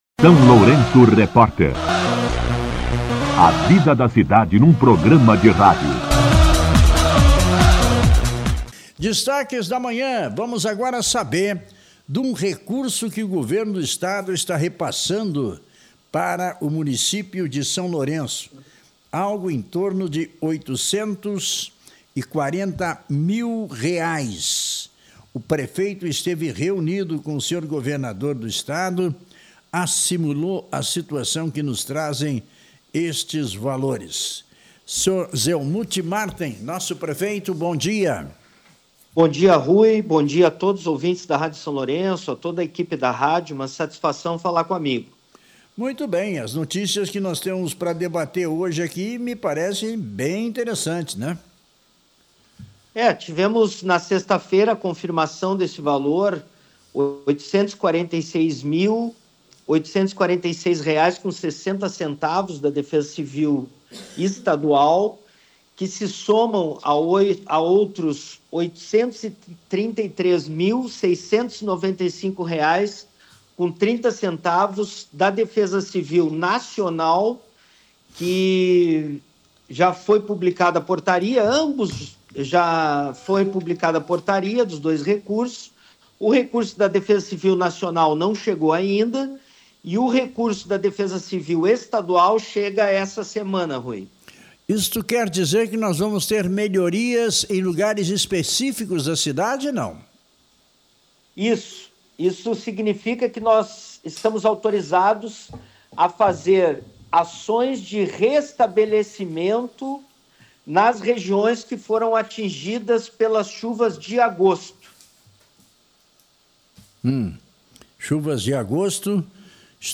Entrevista com o prefeito Zelmute Marten
entrevista-prefeito.mp3